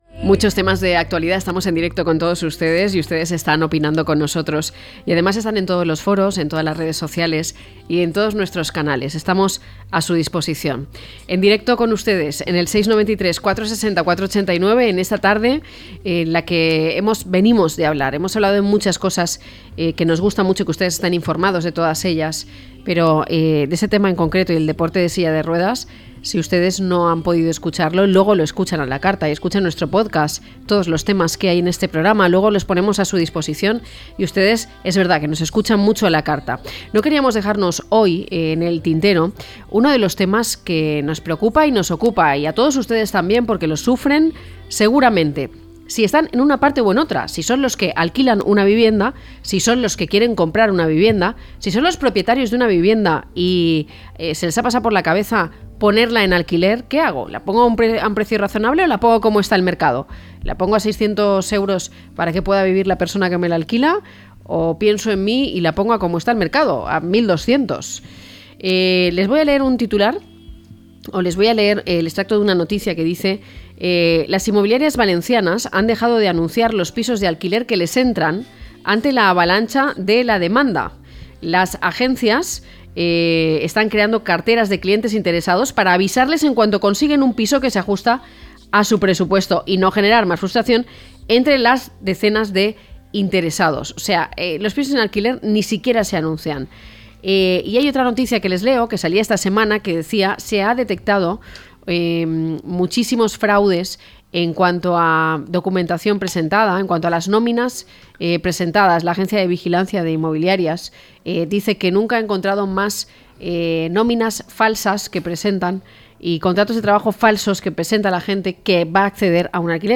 0514-LTCM-TERTULIA.mp3